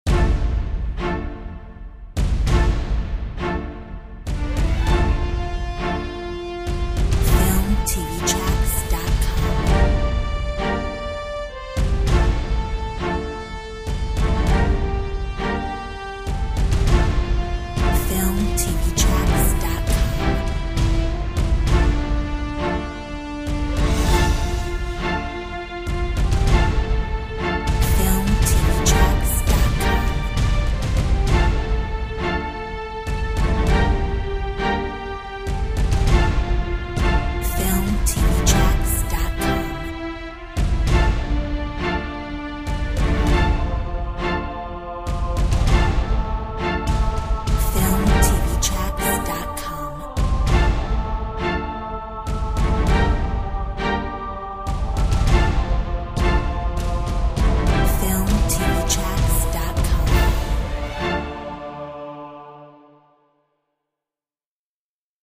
Corporate , POP